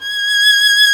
Index of /90_sSampleCDs/Roland - String Master Series/STR_Violin 1-3vb/STR_Vln3 % marc
STR VLN3 G#5.wav